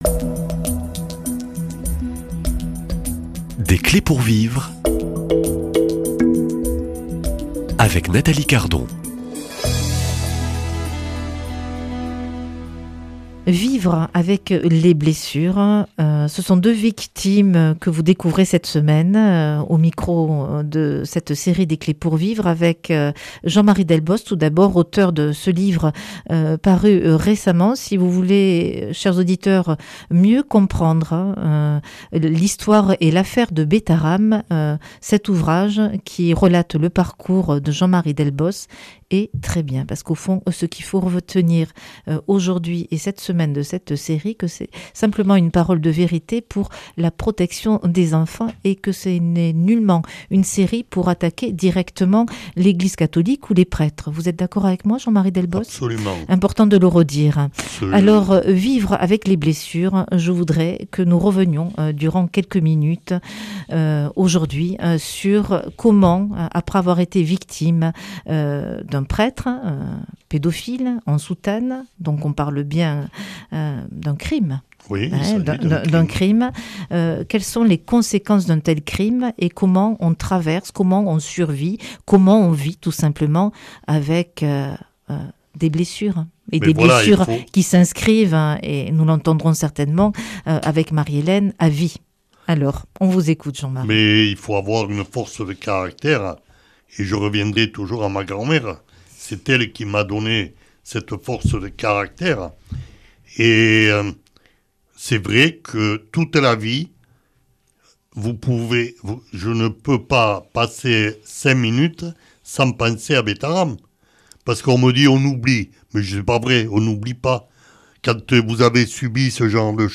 Que reste-t-il quand l’enfance a été marquée par la violence ? Dans cet entretien